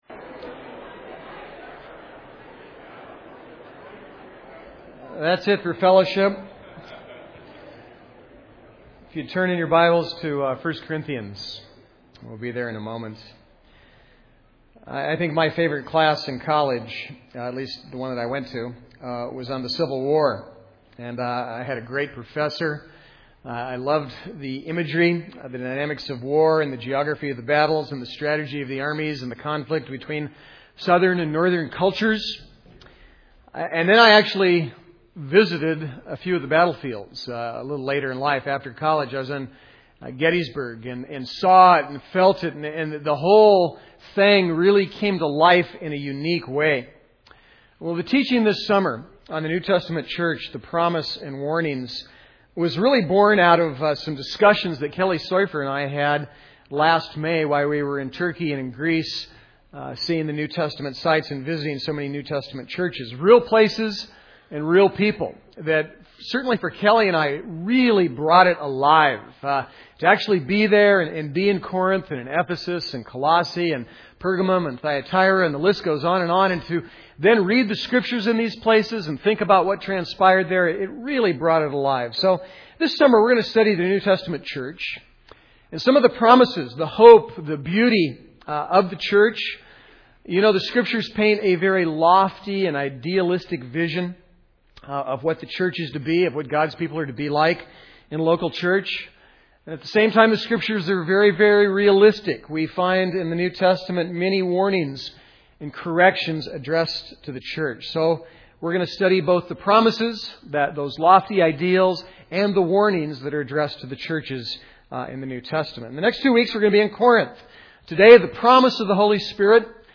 The New Testament Church Service Type: Sunday Corinth Promise